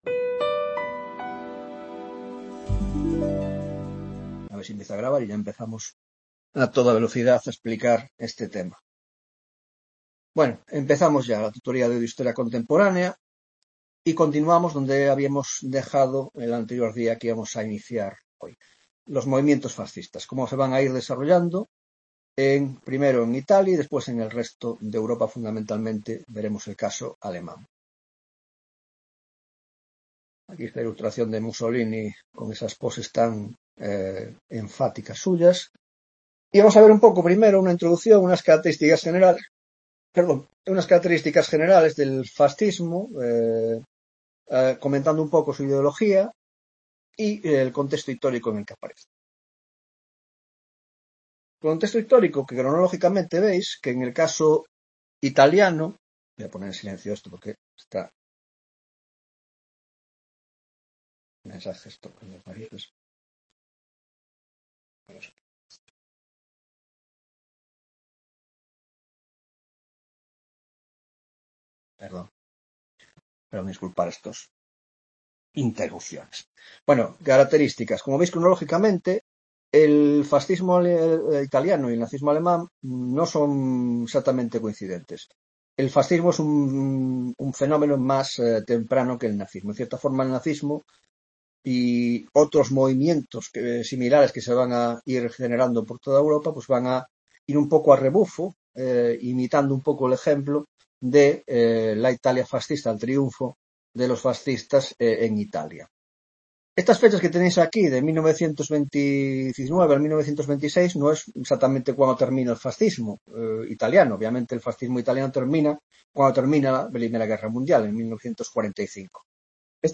19ª tutoría de Historia Contemporánea: 1) Los Fascismos (1ª parte): 1.1) Introducción general: Contexto e ideario; 1..3) Orígenes y evolución del Fascismo Italiano; 1.2) La Huelga de 1922 y la "Marcha sobre Roma" , 1.3) La llegada al poder y el giro al Estado Totalitario